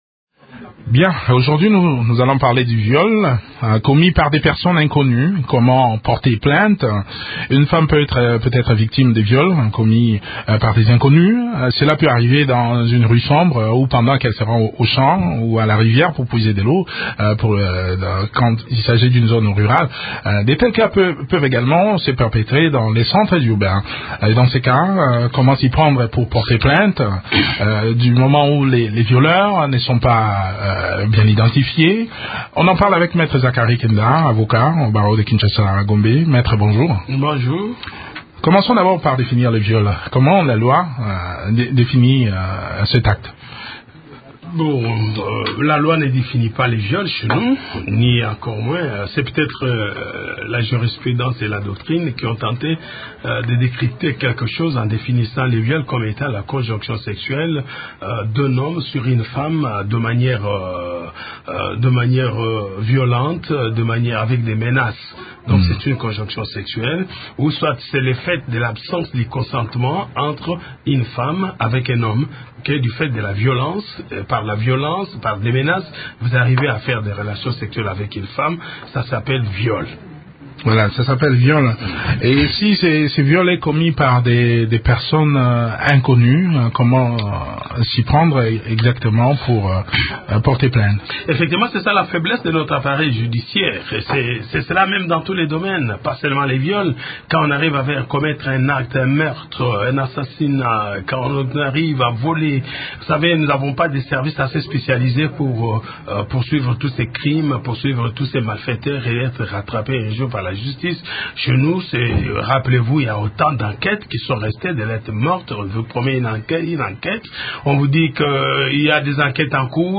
avocat au barreau de Kinshasa/Gombe.